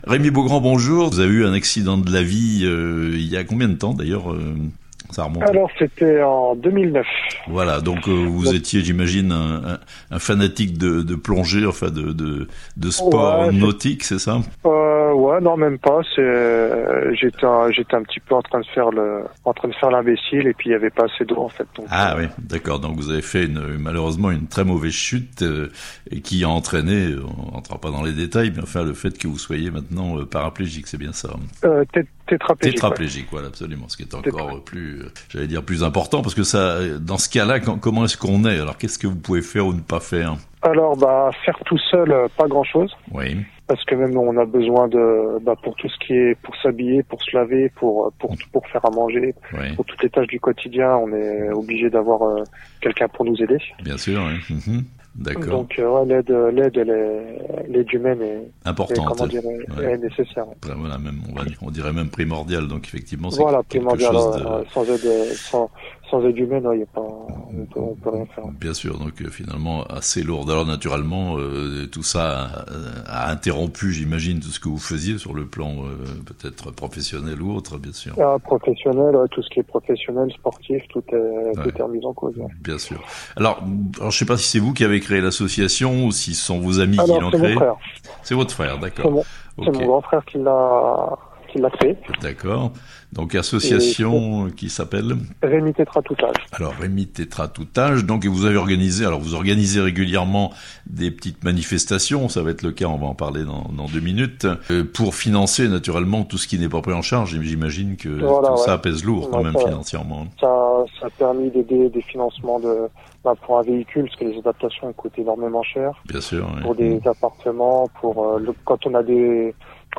Une représentation théâtrale au profit des personnes en situation de handicap (interview)